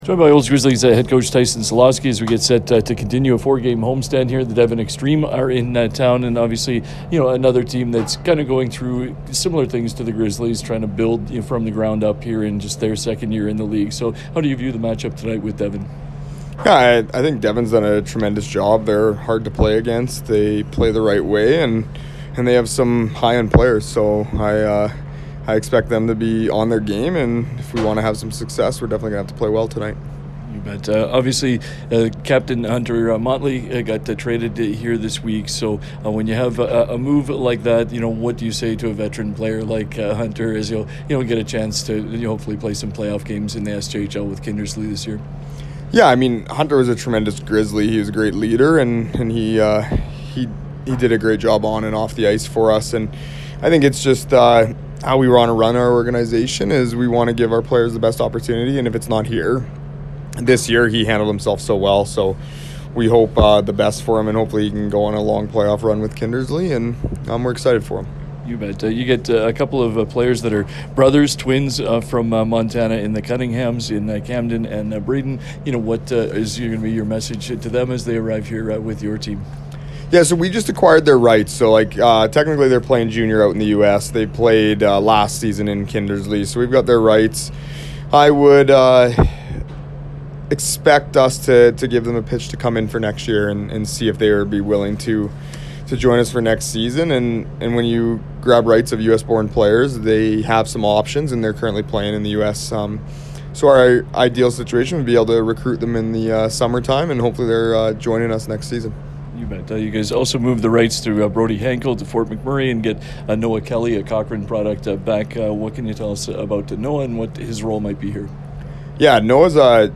pre-game conversation